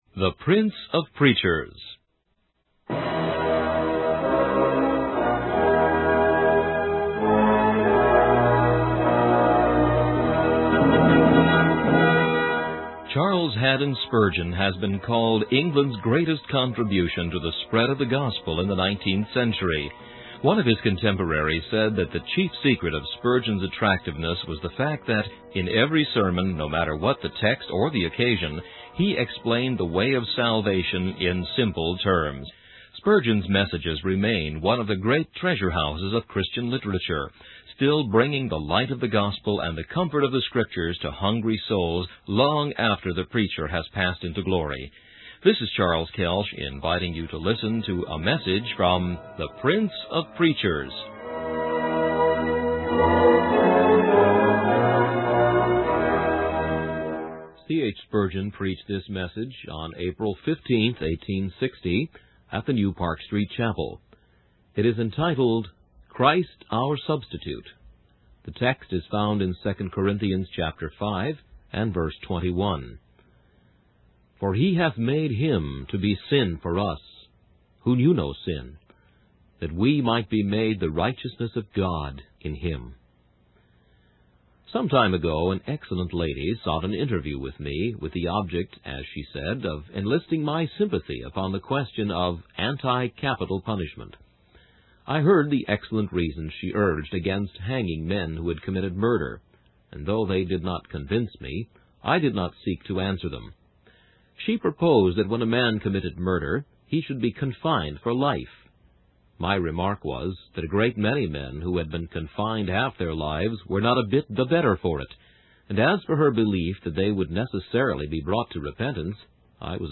In this sermon, the preacher emphasizes the importance of standing firm in the face of false teachings and worldly philosophies. He encourages the listeners to hold fast to the faith that was delivered to them and to resist any attempts to sway them from it.